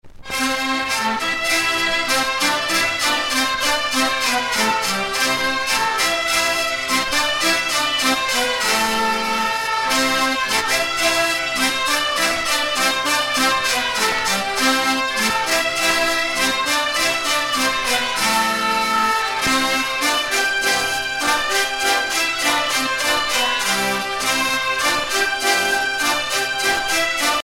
danse : bal à deux
Pièce musicale éditée